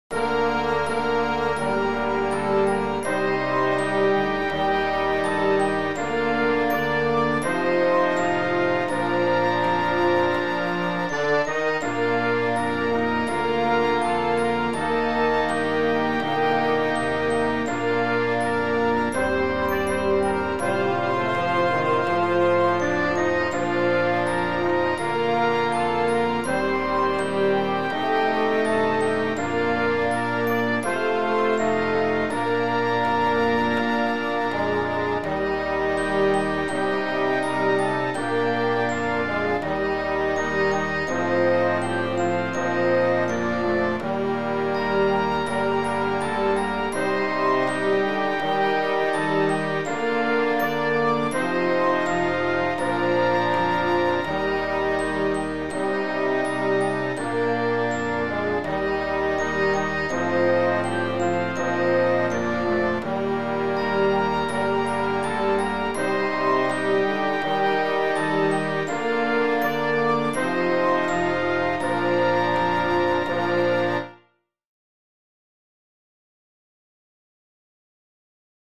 Audio : 16.8.2022 celebrazione religiosa a S.Rocco di Bernezzo